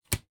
pokerplay.ogg